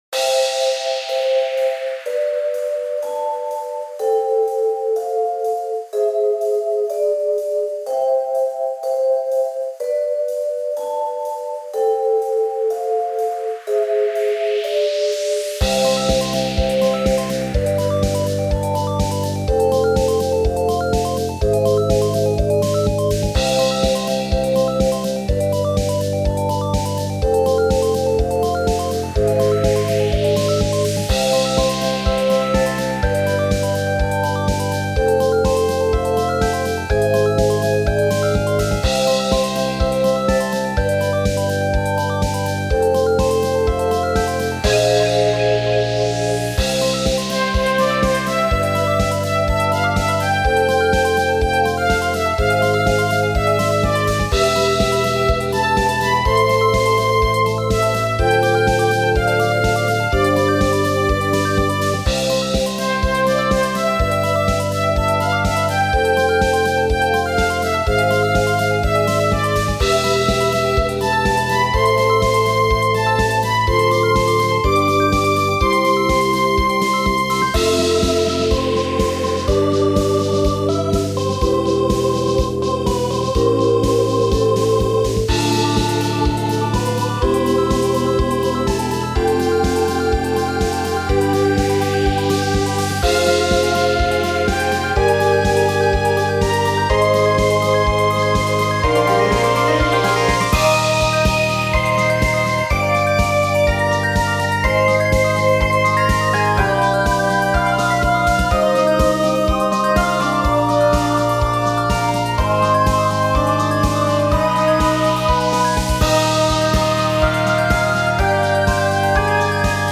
至る所からきこえるピコピコ音がいい感じ？
裏コード♭9など音楽理論の技法を活用。